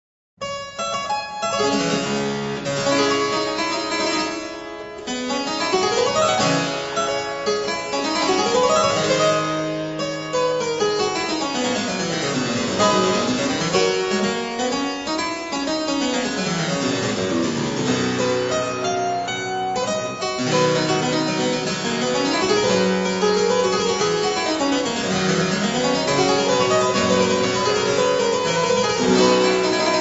cembalo
• clavicembalo
• passacaglie
• toccate
• registrazione sonora di musica